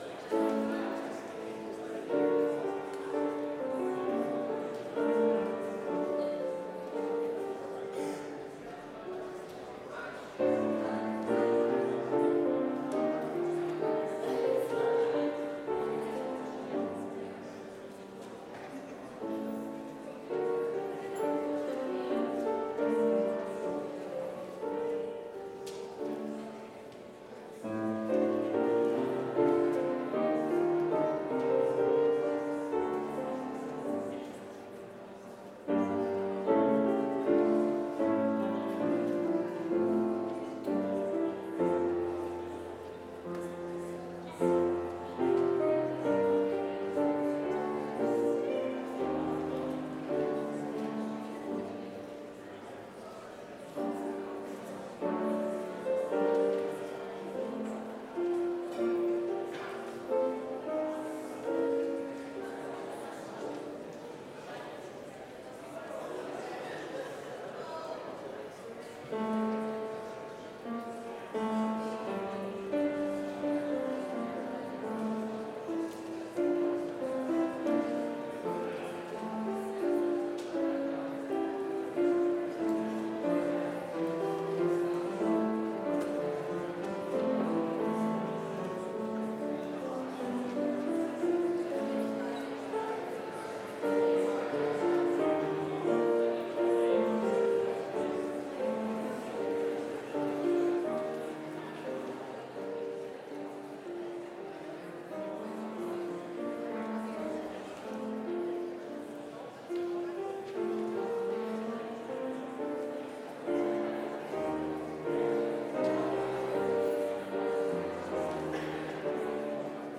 Complete service audio for Chapel - Friday, October 25, 2024